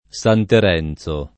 [ S an ter $ n Z o ]